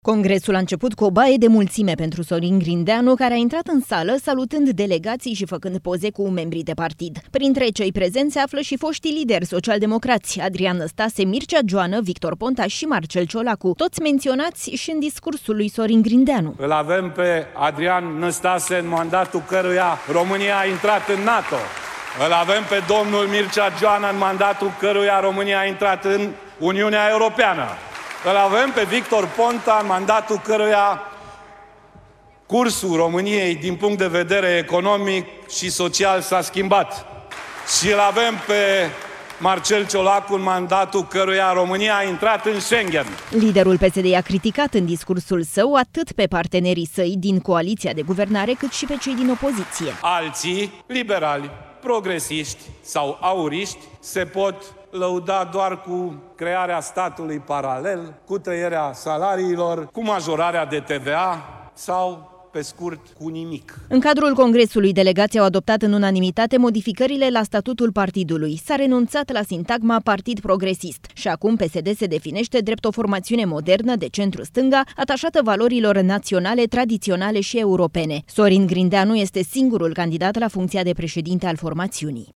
Congres cu multă agitație în București. Sorin Grindeanu a fost numit oficial noul președinte al PSD.